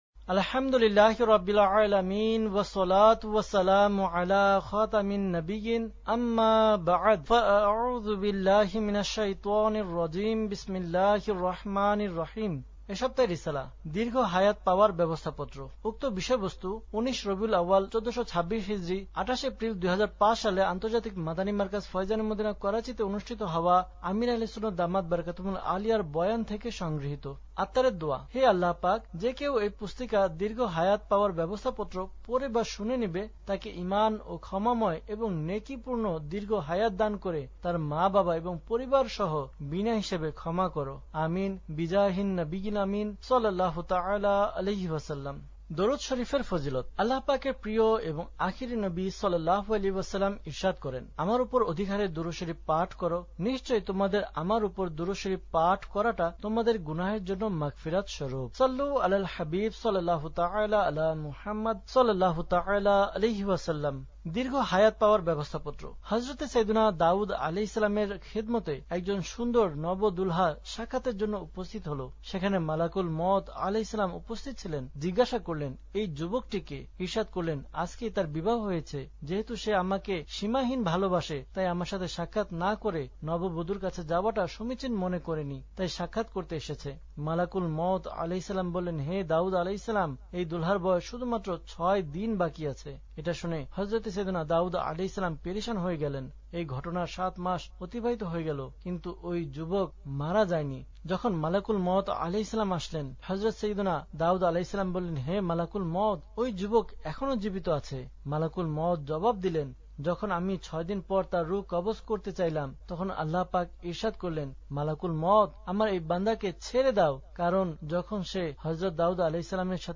Audiobook - দীর্ঘ হায়াত পাওয়ার ব্যবস্থাপত্র (Bangla)